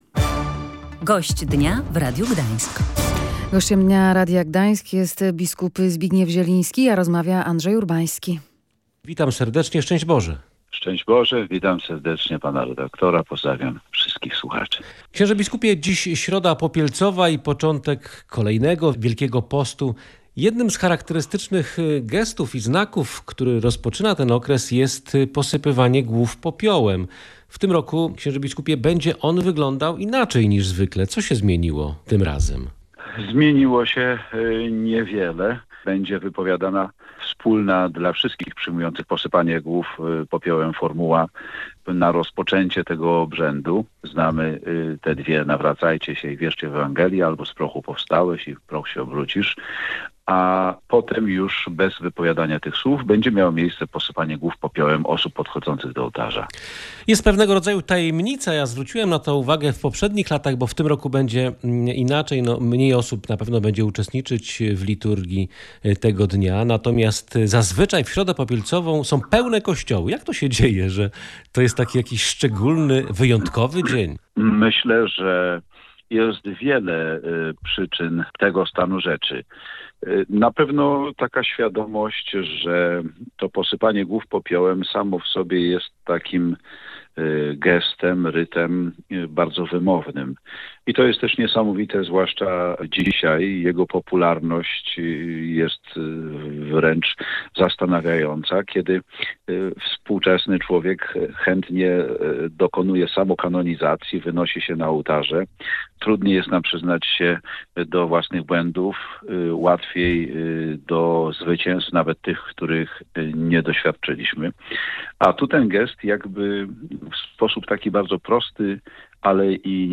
Zaczynający się w środę Wielki Post to dobry czas, by przekierować swoją uwagę na drugiego człowieka, szczególnie w czasie epidemii – mówił w Radiu Gdańska biskup pomocniczy Archidiecezji Gdańskiej Zbigniew Zieliński. Szczególnie naszym wsparciem powinniśmy objąć osoby starsze i chore.